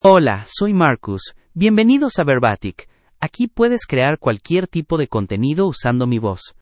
MaleSpanish (United States)
MarcusMale Spanish AI voice
Marcus is a male AI voice for Spanish (United States).
Voice sample
Male
Marcus delivers clear pronunciation with authentic United States Spanish intonation, making your content sound professionally produced.